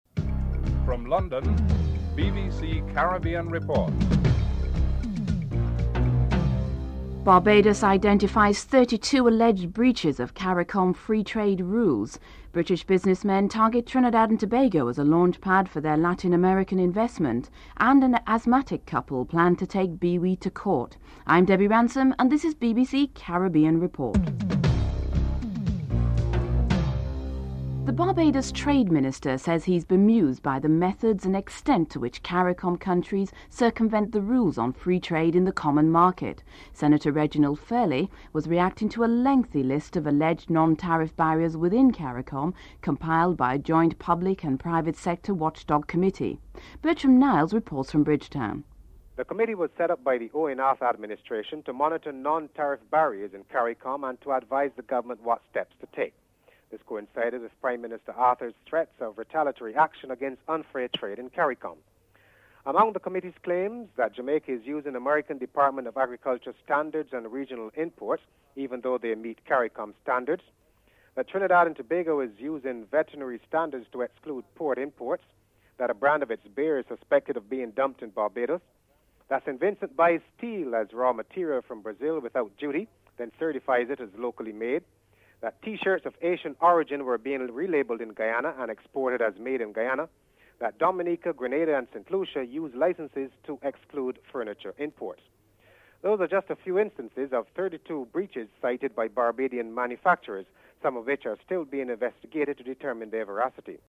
Tony Baldry, Britain's Foreign Office Minister for the Caribbean explains Trinidad's important role.
Interview